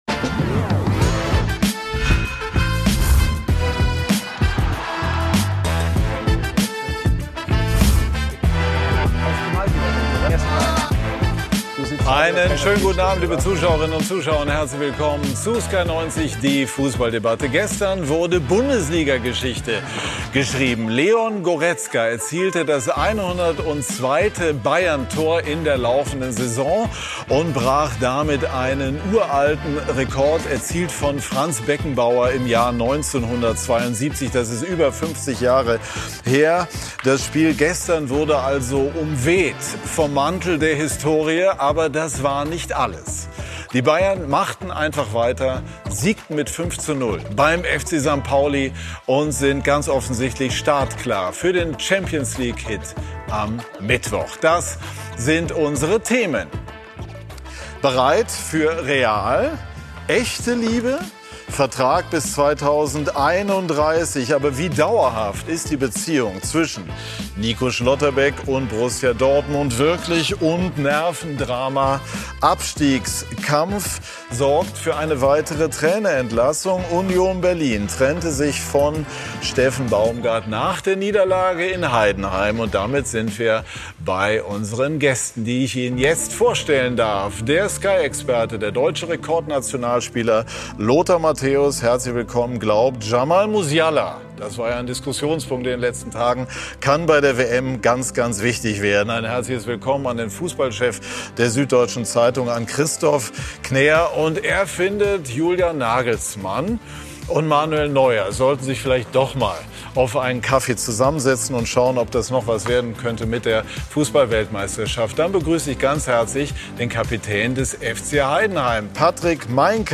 Beschreibung vor 6 Tagen Kontrovers, unterhaltsam, meinungsbildend – mit Sky90 präsentiert Sky den umfassendsten Fußball-Live-Talk Deutschlands.